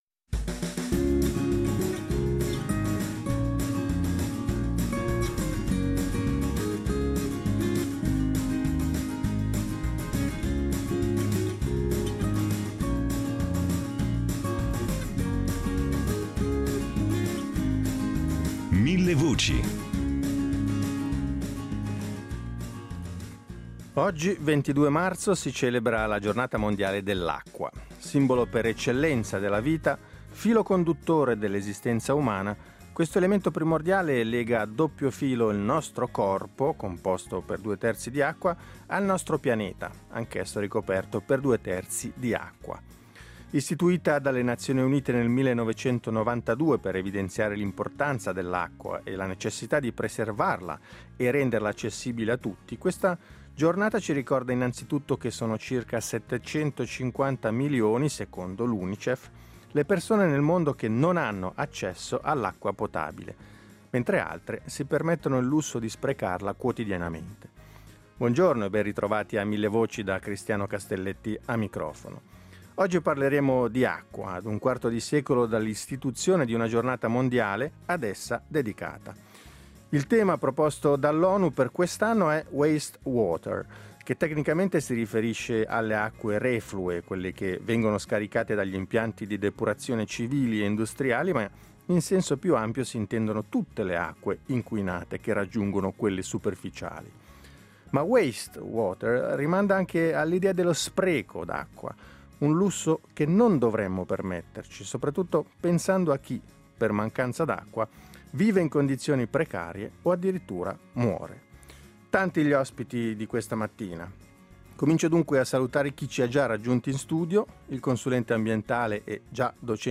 Il tema proposto dall'ONU per questo anno è waste water: tecnicamente, con tale termine si indicano i reflui che vengono scaricati nei corsi d’acqua dagli impianti di depurazione civili e industriali; in senso più ampio, invece, ci si riferisce a tutte le acque inquinate che raggiungono quelle superficiali. Discuteremo del senso di questa giornata in compagnia di esperti di questioni idriche e ambientali.